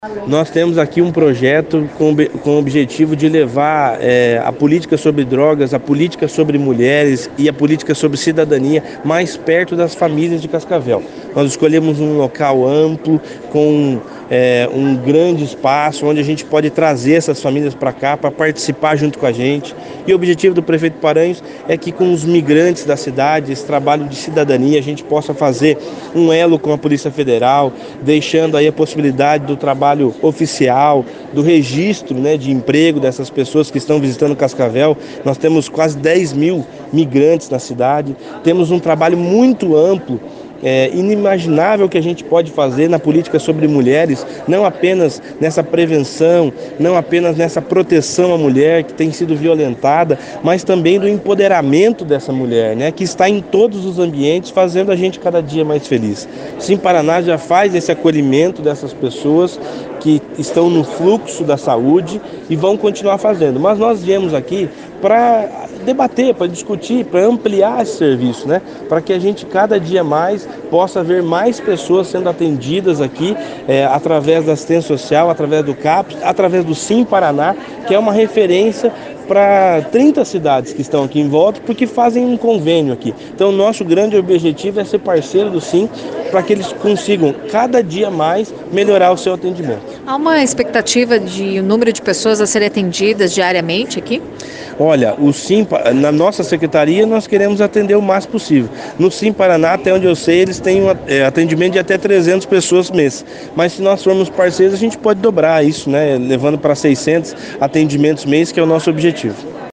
O secretário da SESD, Misael Junior, comenta que objetivo é fazer um trabalho de cidadania.